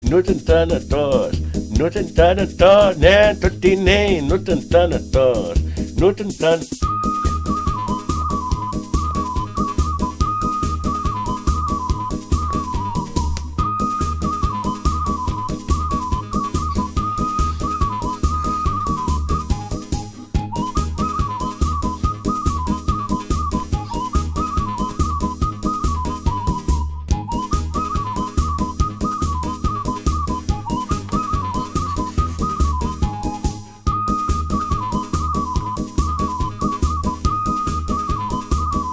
веселые
без слов
смешные
румынские
сумасшедшие